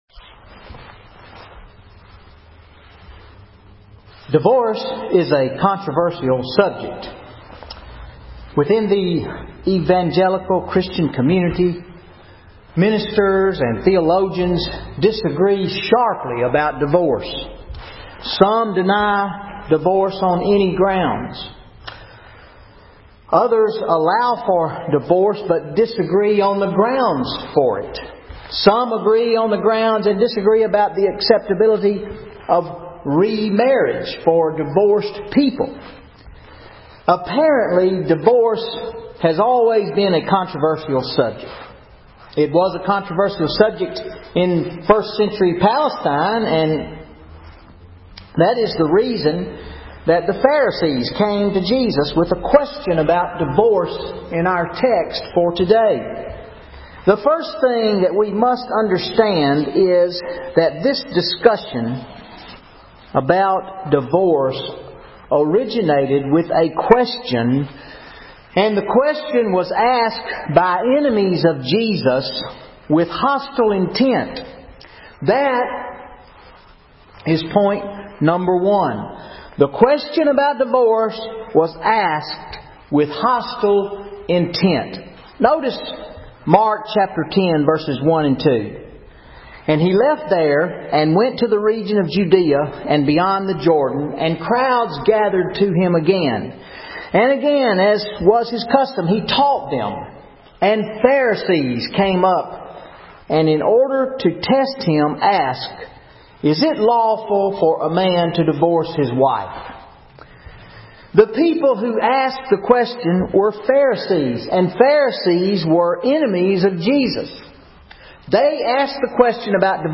Sermon March 3, 2013 What did Jesus have to say about Divorce?